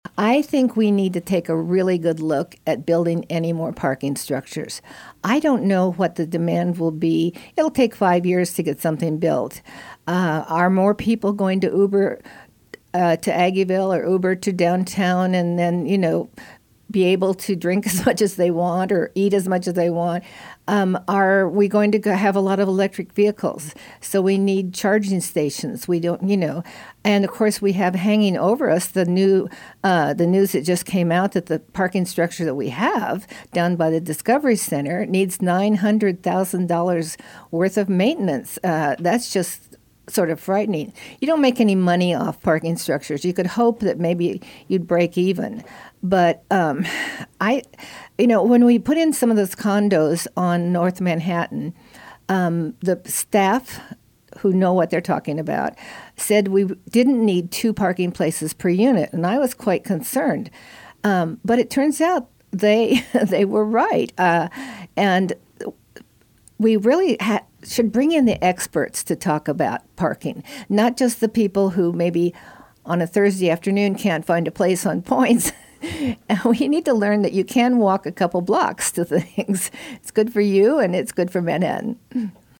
News Radio KMAN has invited candidates seeking a seat on the Manhattan City Commission and Manhattan-Ogden USD 383 school board to be interviewed ahead of Election Day.